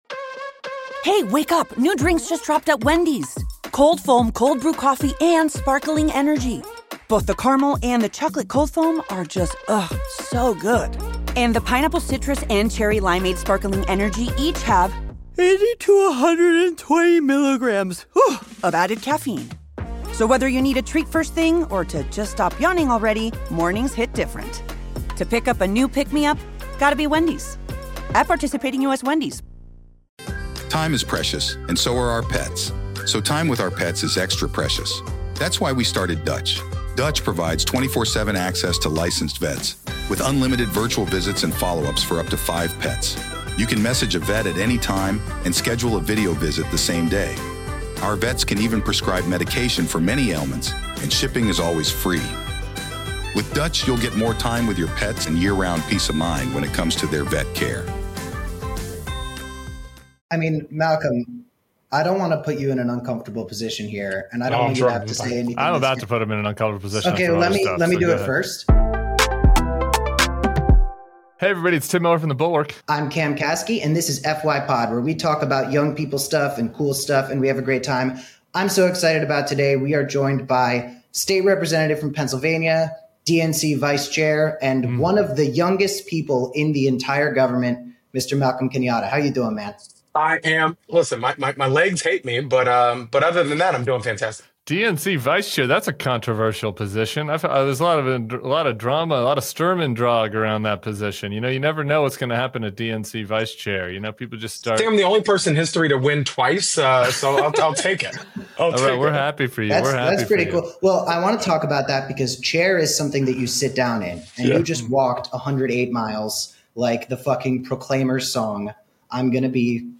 State Rep. and DNC Vice Chair Malcolm Kenyatta joins Tim Miller and Cameron Kasky for a powerful conversation on the Democratic Party’s future.